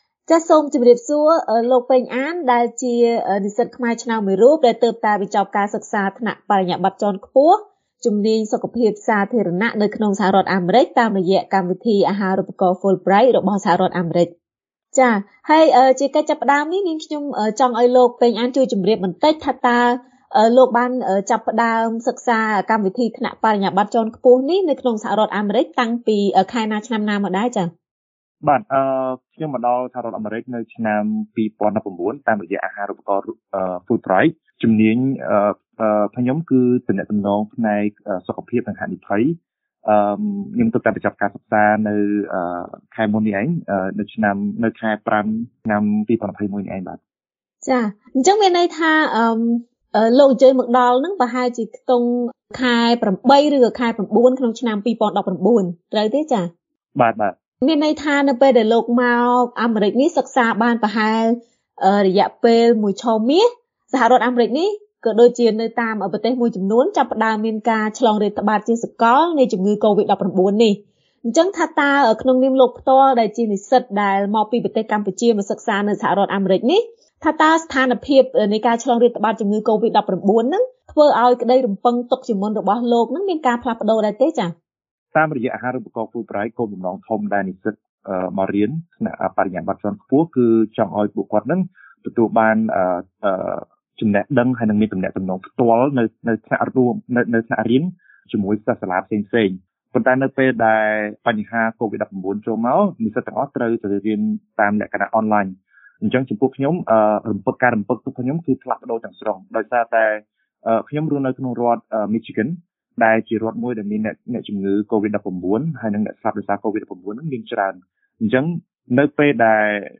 បទសម្ភាសន៍ VOA៖ និស្សិត Fulbright ជម្នះសម្ពាធផ្លូវចិត្តពេលសិក្សានៅអាមេរិក ក្នុងពេលកូវីដ១៩រាតត្បាត